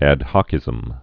(ăd hŏkĭz-əm, hōkĭz-)